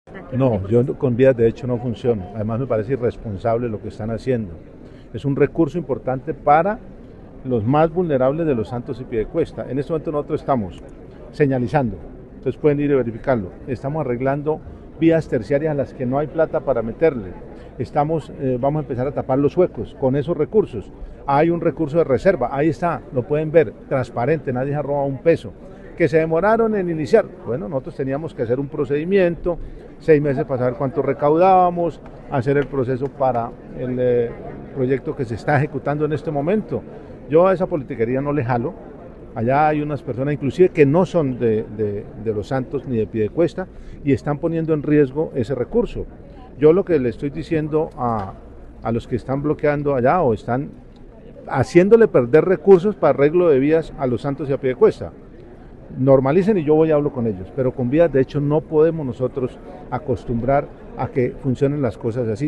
Sobre los bloqueos y protestas en el peaje de La Mesa de Los Santos esto dice el gobernador de Santander: